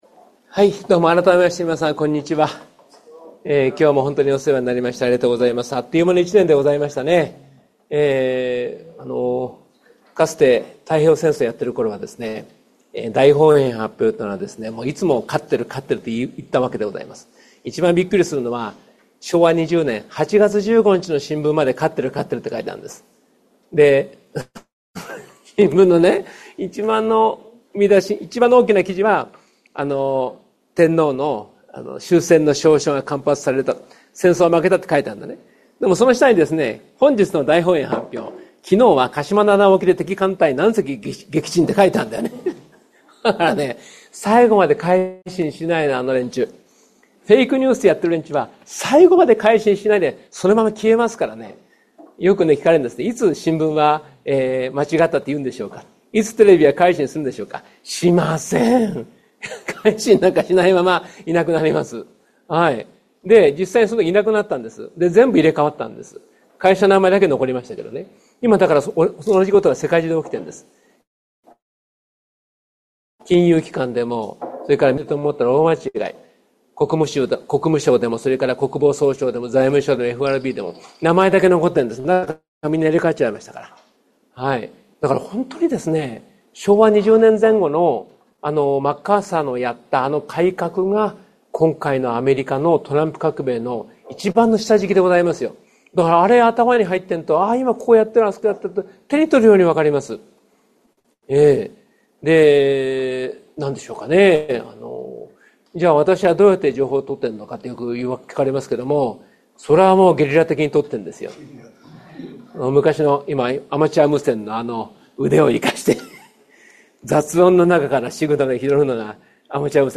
第111回NSP時局ならびに日本再生戦略講演会